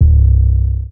808 - Reincarnation.wav